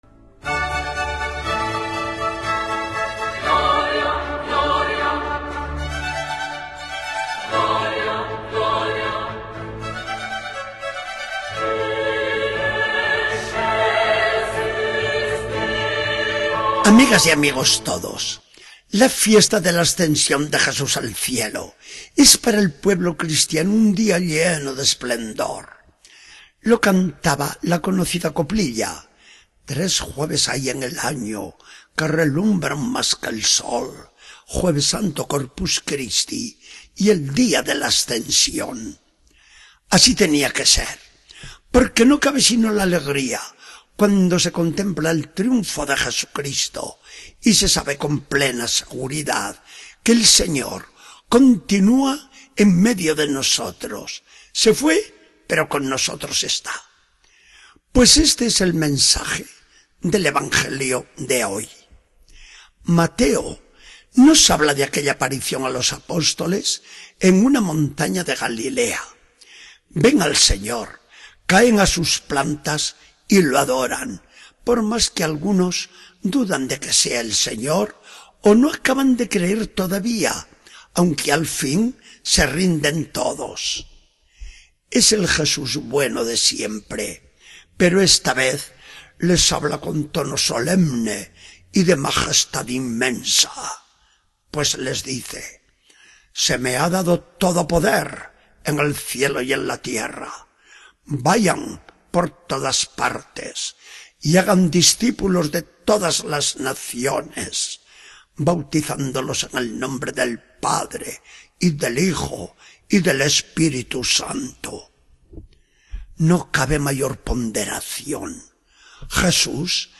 Charla del día 1 de junio de 2014. Del Evangelio según San Mateo 28, 19-20.